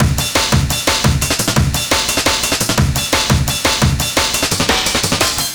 cw_amen13_173.wav